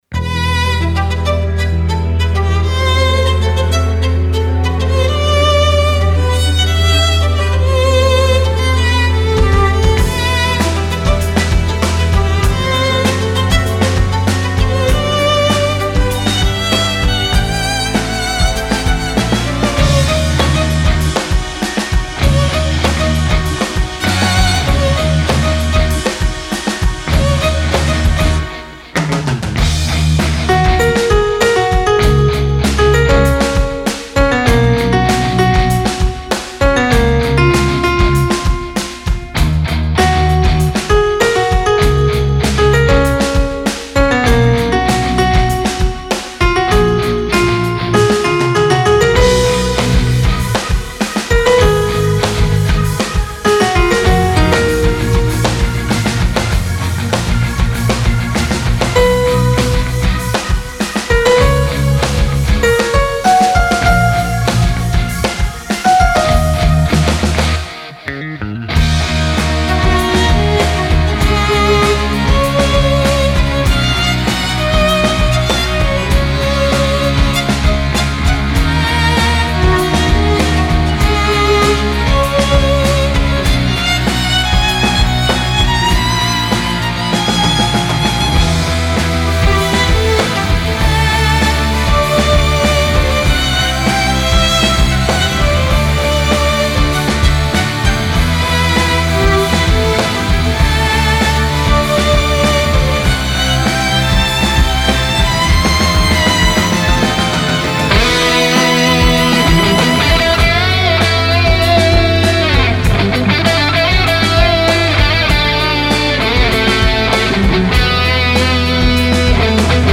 フリーBGM イベントシーン 熱い・高揚
フェードアウト版のmp3を、こちらのページにて無料で配布しています。